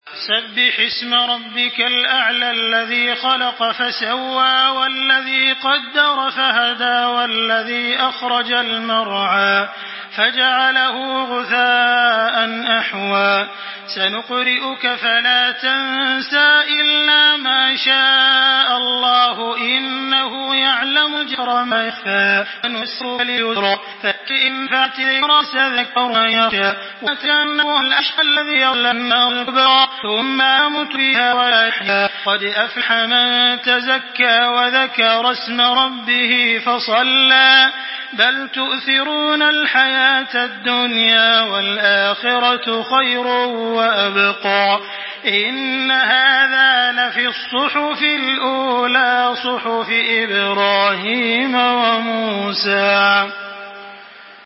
دانلود سوره الأعلى توسط تراويح الحرم المكي 1425
مرتل